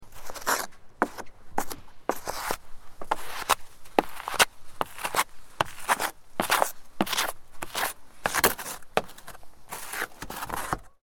Sound Effects / Street Sounds 28 Aug, 2025 Car Windshield Ice Scraping With Plastic Scraper Sound Effect Read more & Download...
Car-windshield-ice-scraping-with-plastic-scraper-sound-effect.mp3